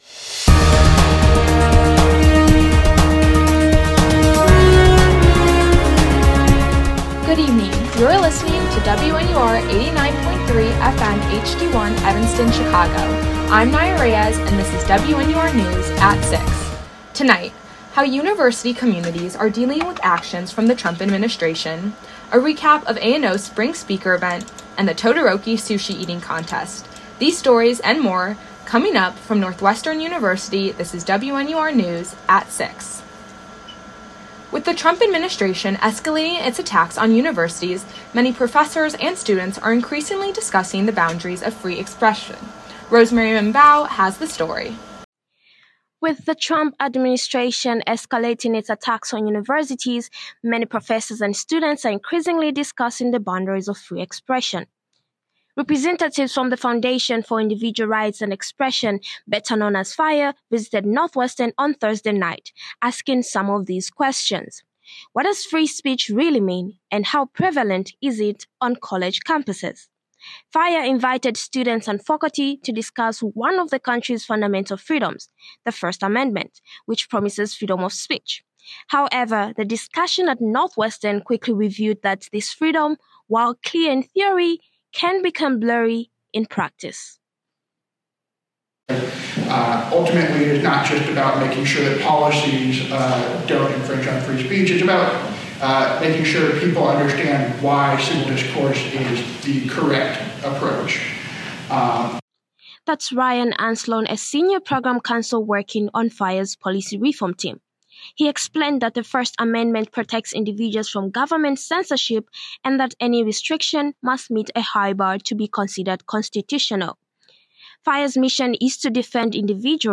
WNUR News broadcasts live at 6 pm CST on Mondays, Wednesdays, and Fridays on WNUR 89.3 FM.